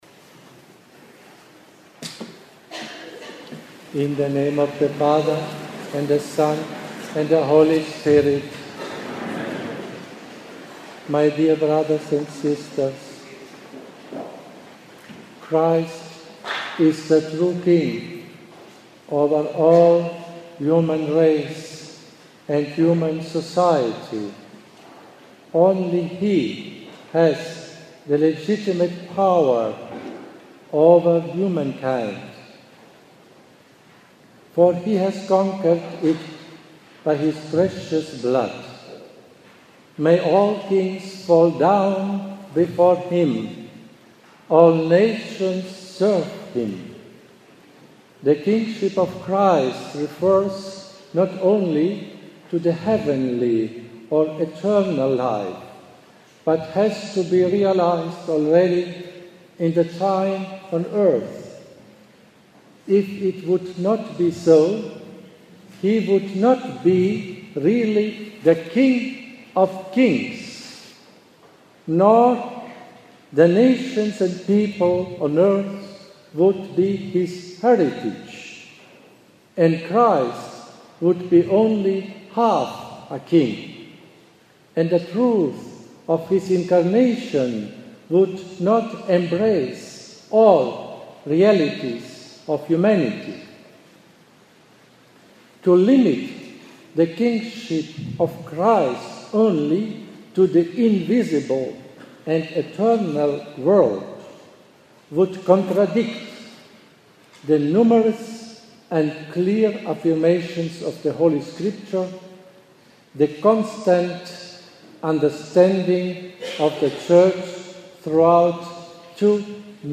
bishop-schneider-st-ann-charlotte-oct-2017-kingship-of-christ-sermon.mp3